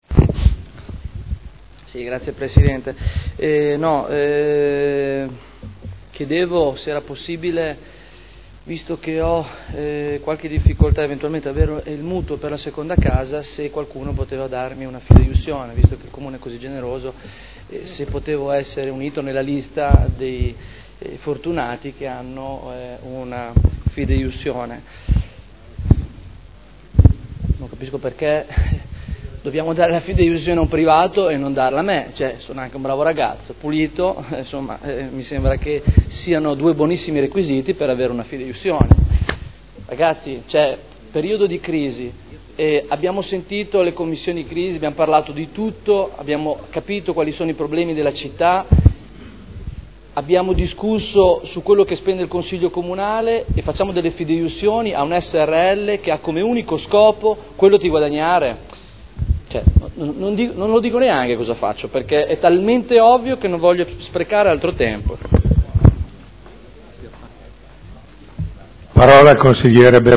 Dichiarazione di voto su proposta di deliberazione: Concessione di garanzia fidejussoria a favore della Banca Popolare dell’Emilia Romagna per i mutui in corso di stipulazione con Equipenta S.r.l.